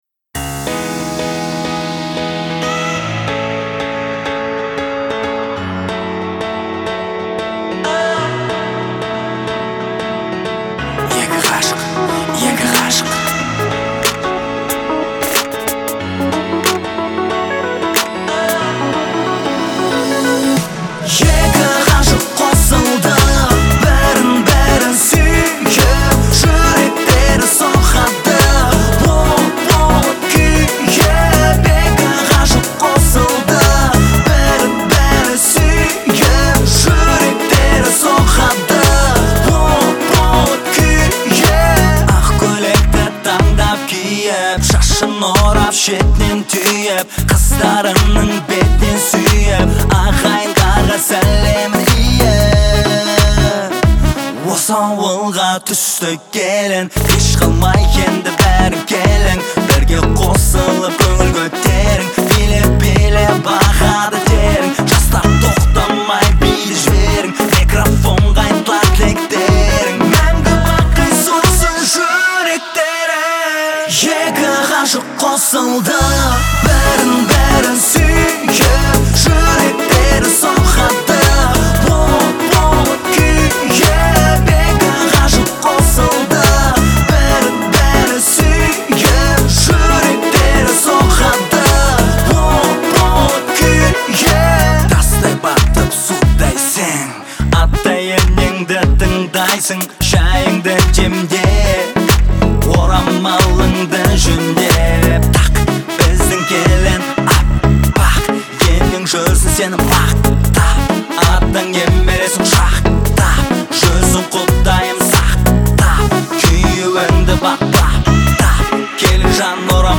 Звучание песни отличается мелодичностью и душевностью